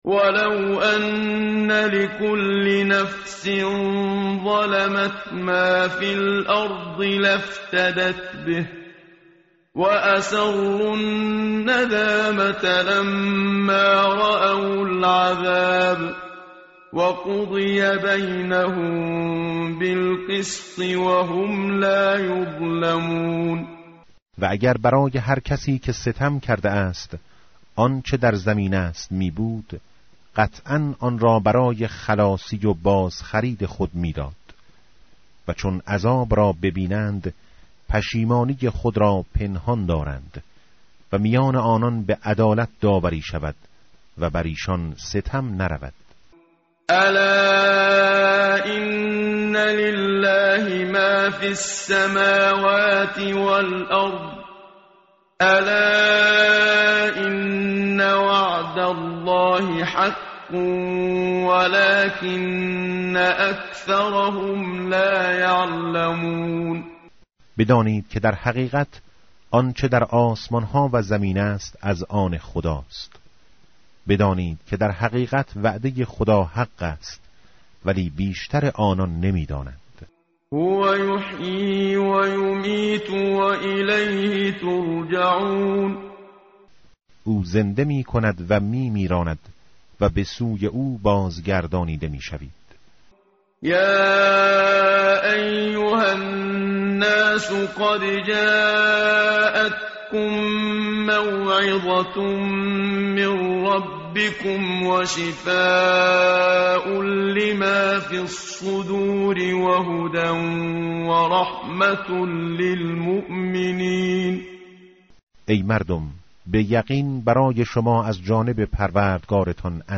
متن قرآن همراه باتلاوت قرآن و ترجمه
tartil_menshavi va tarjome_Page_215.mp3